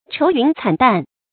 愁云慘淡 注音： ㄔㄡˊ ㄧㄨㄣˊ ㄘㄢˇ ㄉㄢˋ 讀音讀法： 意思解釋： 慘淡：暗淡。